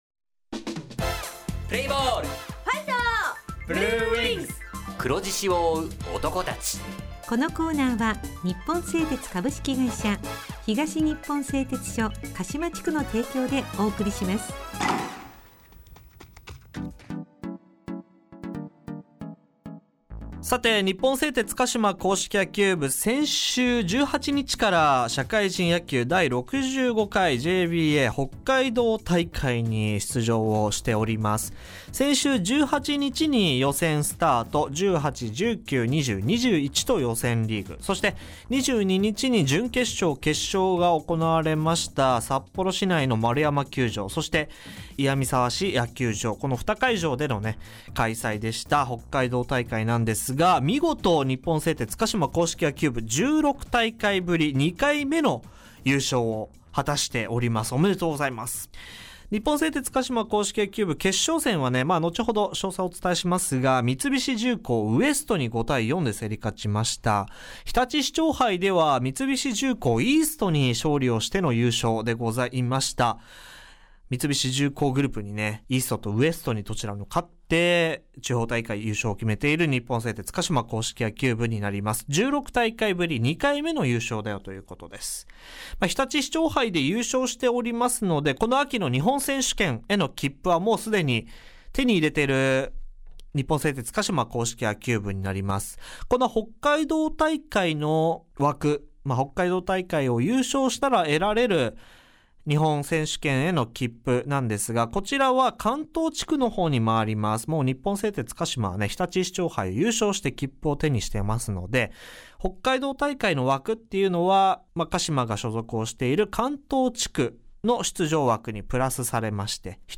6/25放送・JABA北海道大会 振り返り
地元ＦＭ放送局「エフエムかしま」にて鹿島硬式野球部の番組放送しています。